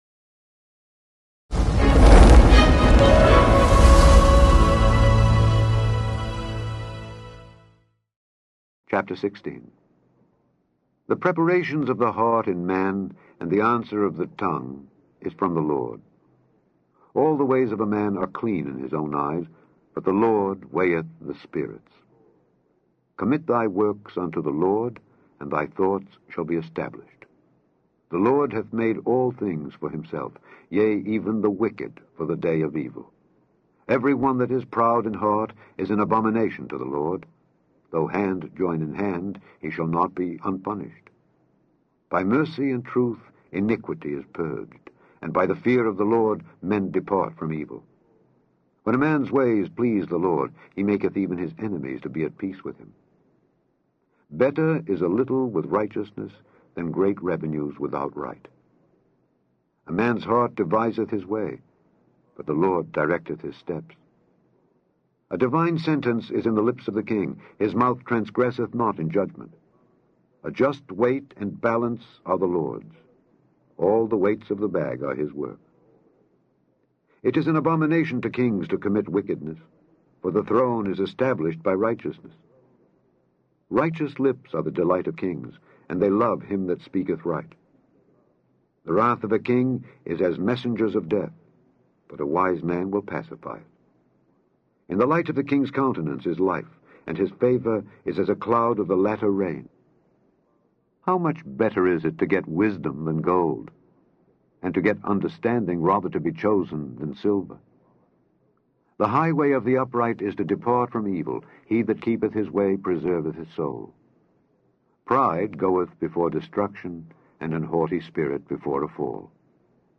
Click on the podcast to hear Alexander Scourby read Proverbs 16.